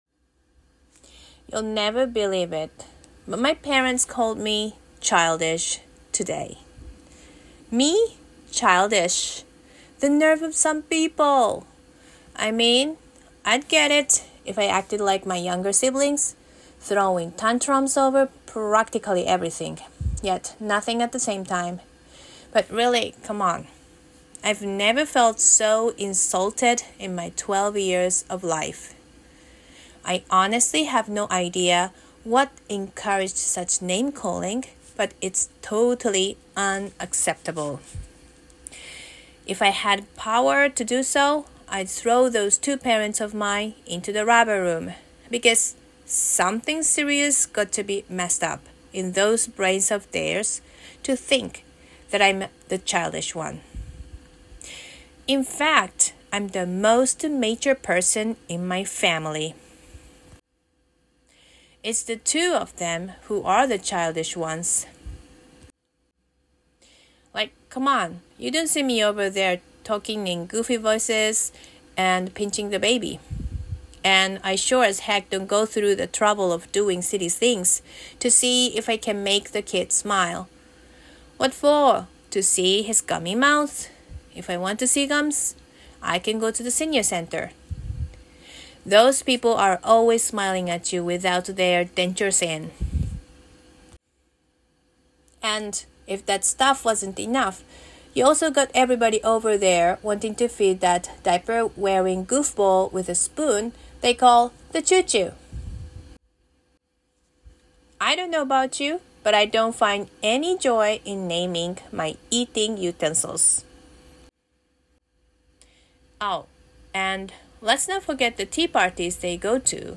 11. Grow Up (EED Monologue)
Genre: Comedic